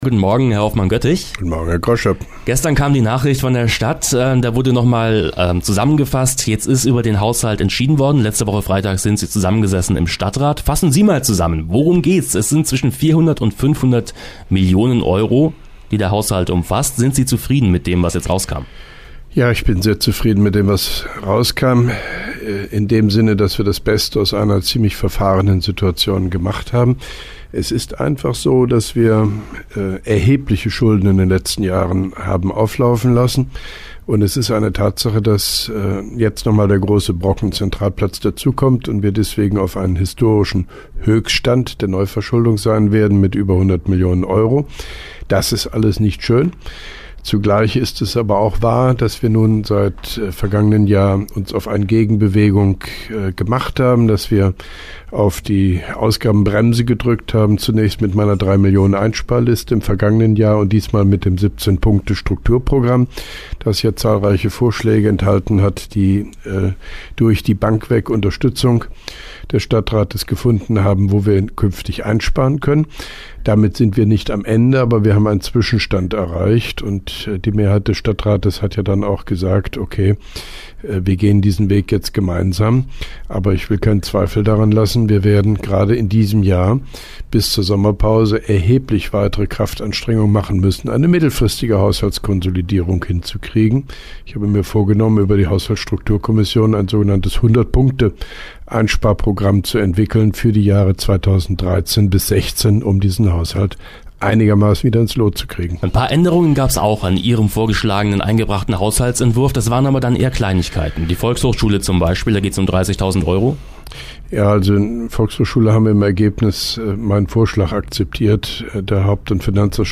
(1) Koblenzer Radio-Bürgersprechstunde mit OB Hofmann-Göttig 28.02.2012
Antenne Koblenz 98,0 am 28.02.2012, ca. 8.40 Uhr (Dauer 05:07 Minuten)